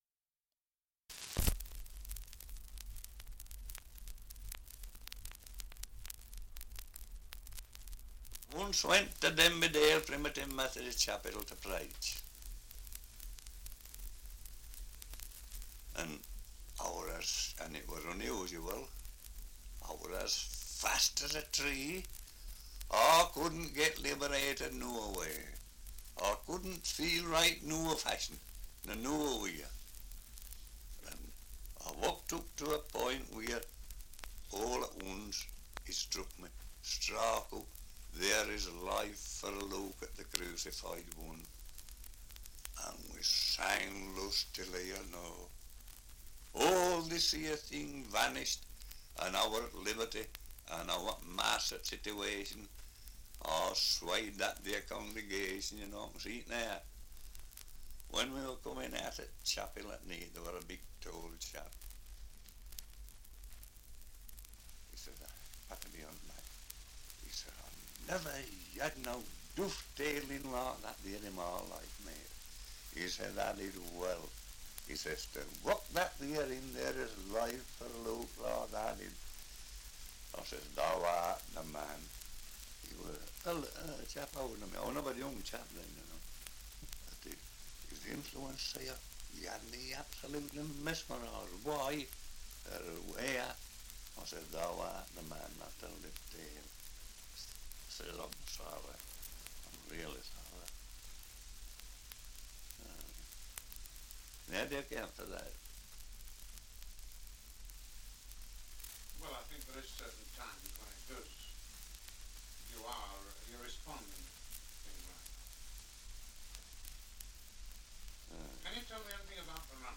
Survey of English Dialects recording in Skelmanthorpe, Yorkshire
78 r.p.m., cellulose nitrate on aluminium